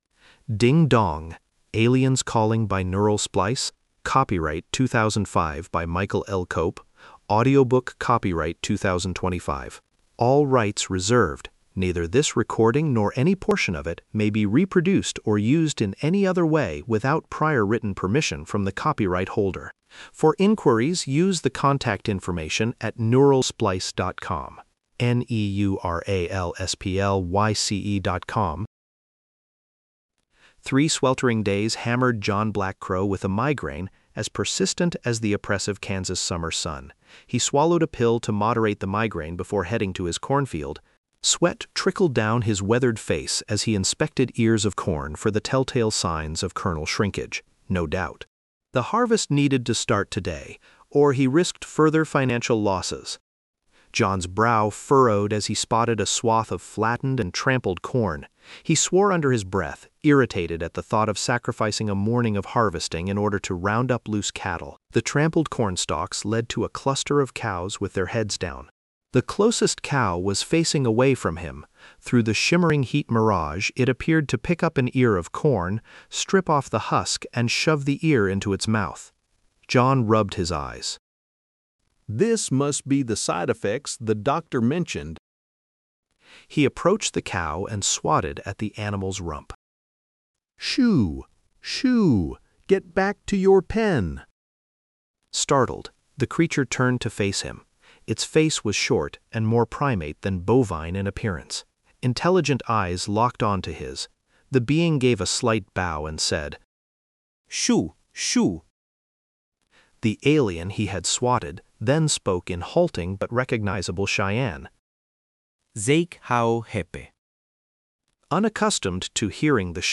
Aliens Calling Donate up to $3 Download ebook Listen to Audiobook Download audiobook